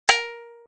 th_sfx_ting_metallic_1.ogg